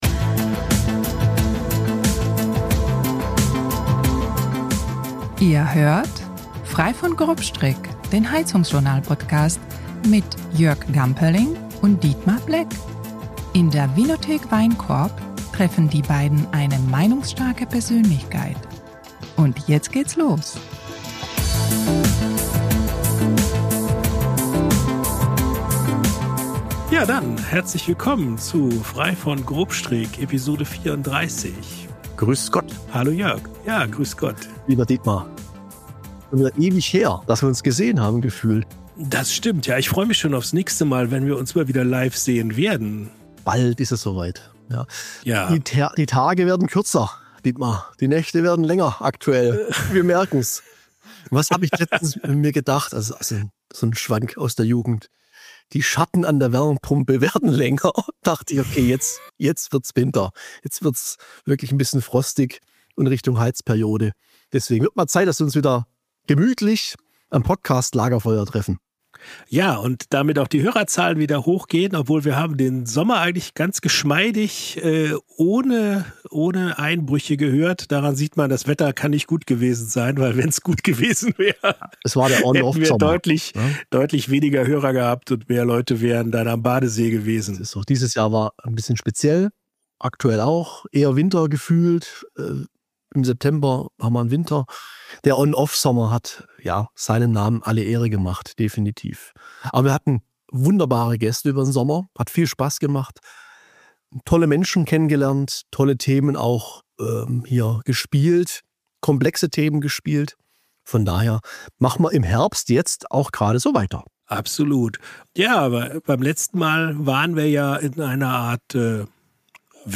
Wo gibt es Parallelen zum SHK-Handwerk und was können die Heizungsexperten vielleicht sogar von den Winzern lernen? Spannende Fragen, über die leidenschaftlich diskutiert wird. Locker bis launig, meinungsstark und informativ, das ist Frei von Grobstrick, der HeizungsJournal- Podcast.